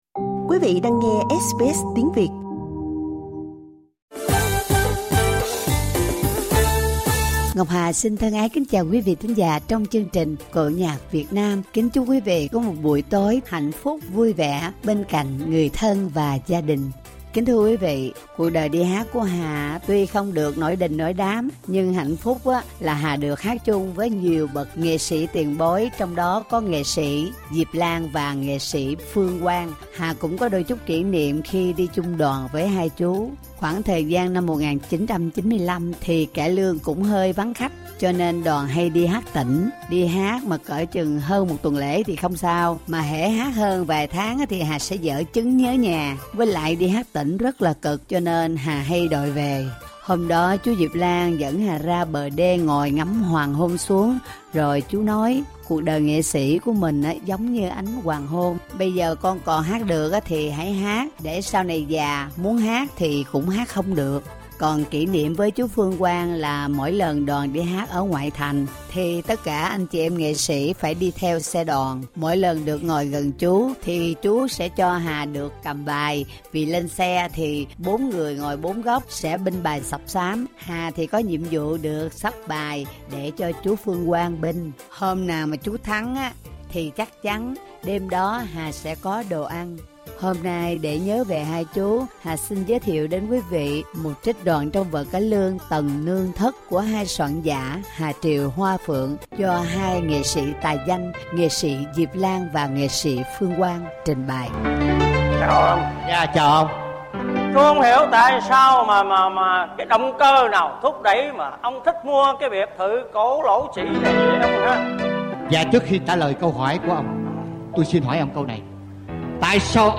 trích đoạn vở cải lương